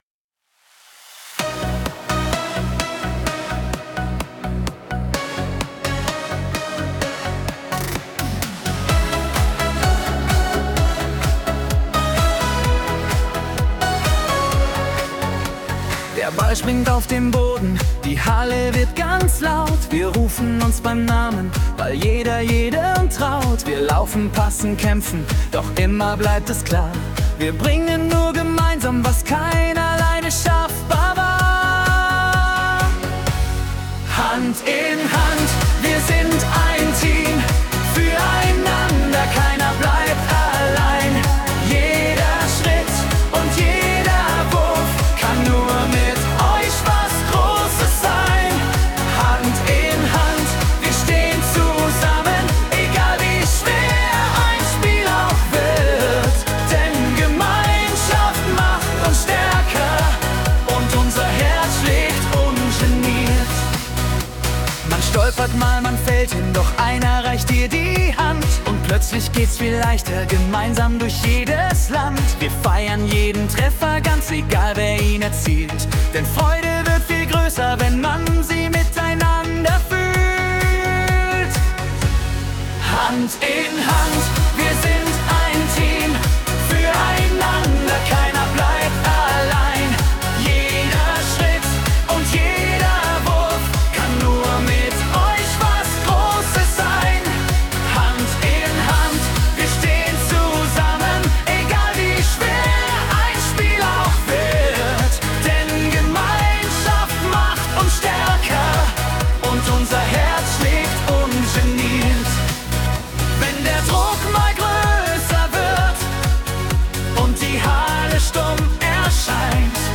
Lied Hand in Hand (KI-generiert):